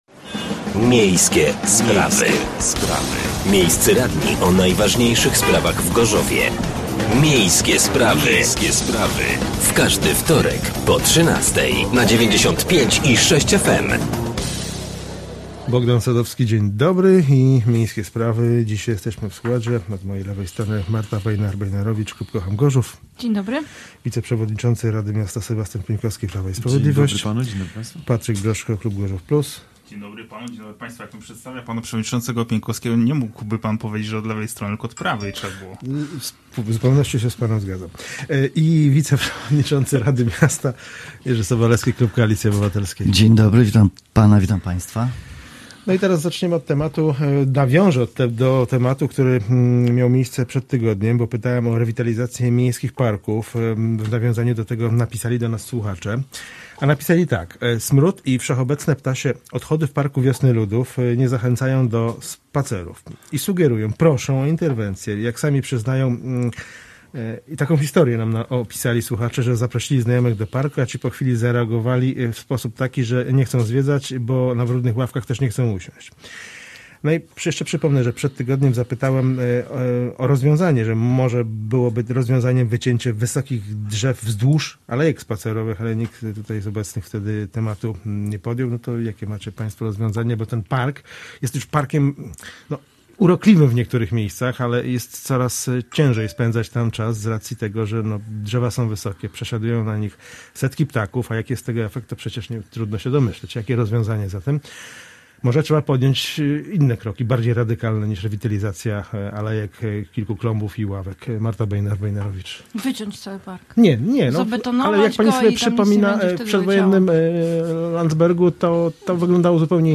Gośćmi byli radni: Marta Bejnar Bejnarowicz (Kocham Gorzów), Sebastian Pieńkowski (Prawo i Sprawiedliwość) , Jerzy Sobolewski (Koalicja Obywatelska) i Patryk Broszko (Gorzów Plus)